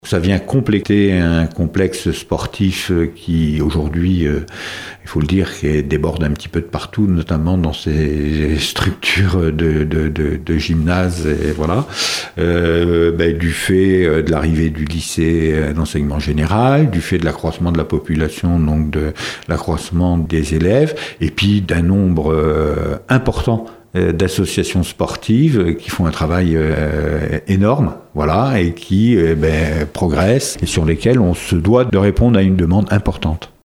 On écoute Jean Gorioux, le président de la Communauté de communes Aunis Sud qui porte le projet :